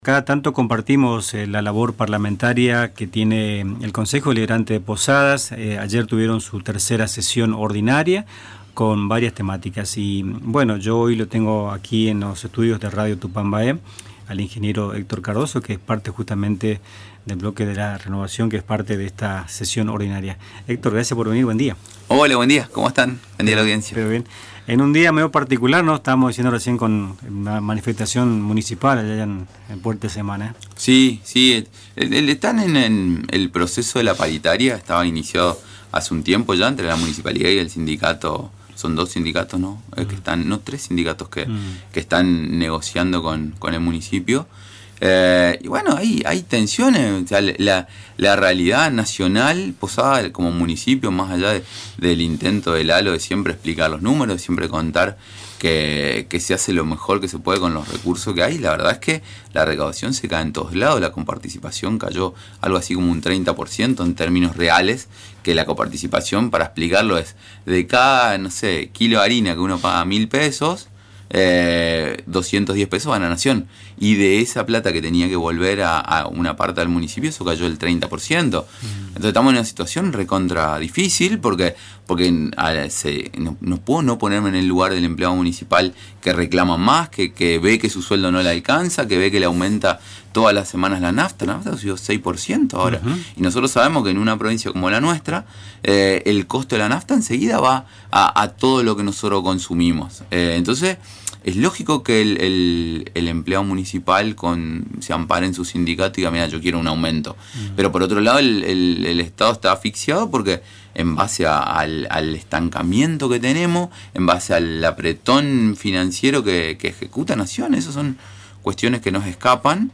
Durante la entrevista abordó la tensión salarial con los trabajadores municipales y el paro en medio de la negociación paritaria, y […]
En Nuestras Mañanas, el concejal de Posadas Héctor Cardozo, integrante del bloque del Frente Renovador, repasó la actualidad política y administrativa de la ciudad tras la tercera sesión ordinaria del Honorable Concejo Deliberante (HCD).